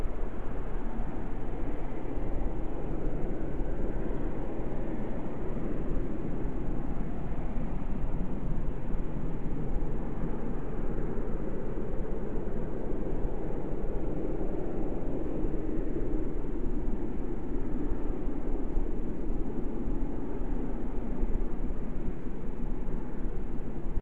2020 WILTON ENVIRONMENTAL NOISE
LISTEN  Flaring 26/7/20 01:05 to 02:10 and probably on.